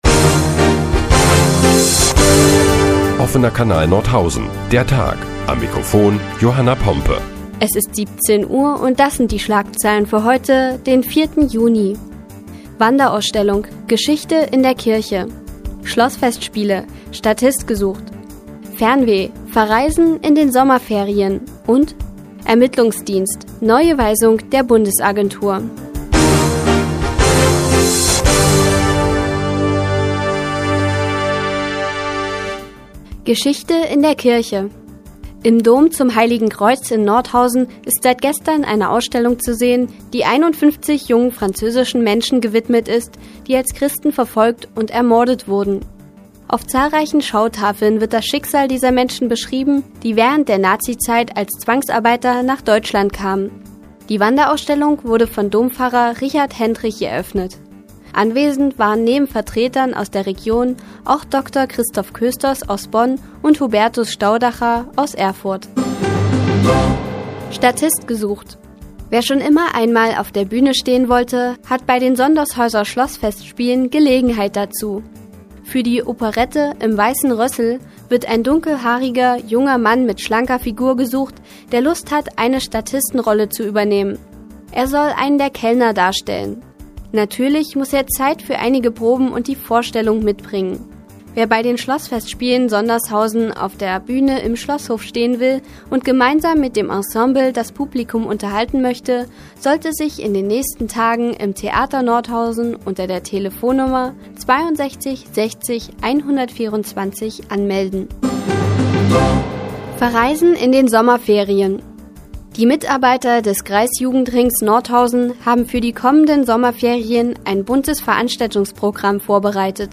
Die tägliche Nachrichtensendung des OKN ist nun auch in der nnz zu hören. Heute geht es unter anderem um eine Wanderausstellung im Nordhäuser Dom und verschärfte Ermittlungen gegen Hartz-IV-Empfänger.